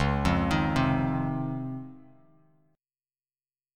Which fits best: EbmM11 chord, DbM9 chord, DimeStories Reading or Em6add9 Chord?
DbM9 chord